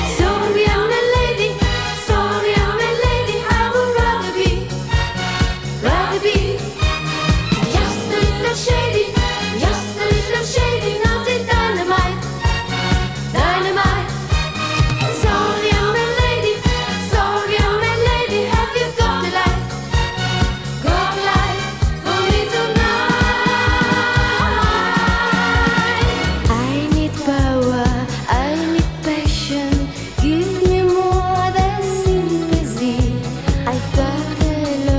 Жанр: Поп музыка / Рок / Танцевальные